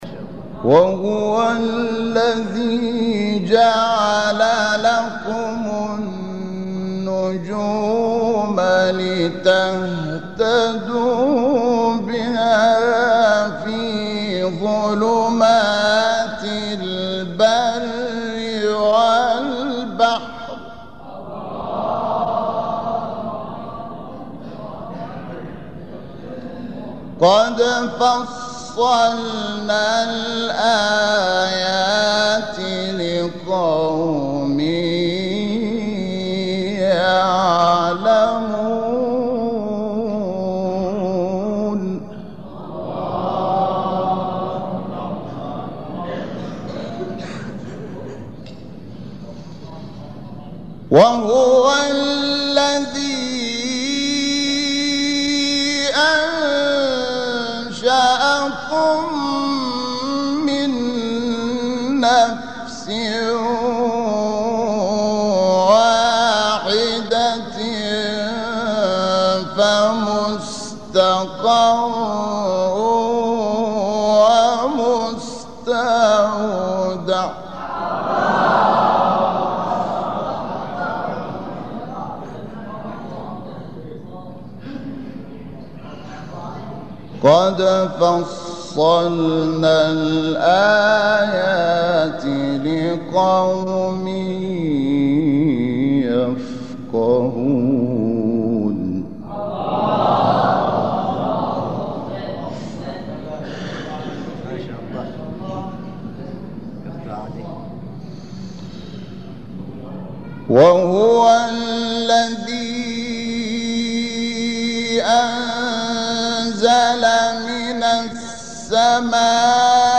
چهارگاه.mp3